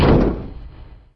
scrape_1.ogg